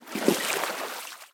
sound / walking / water-11.ogg
water-11.ogg